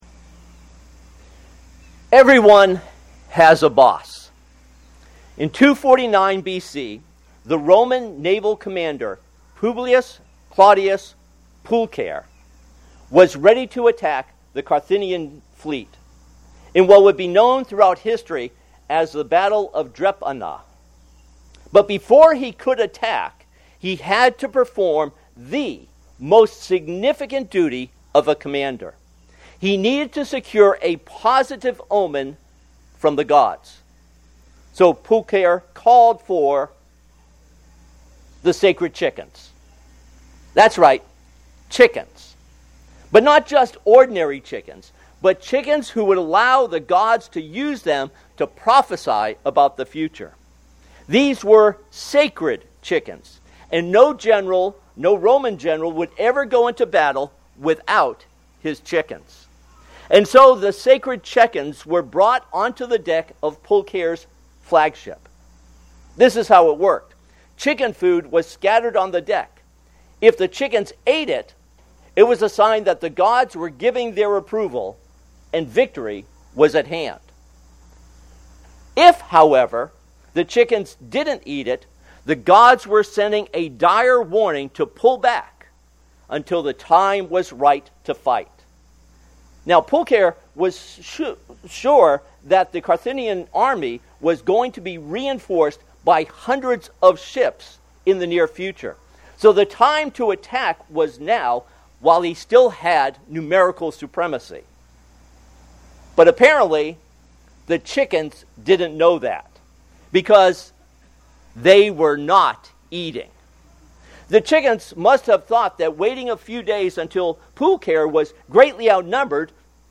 This sermon is based on Philippians 2:5-12.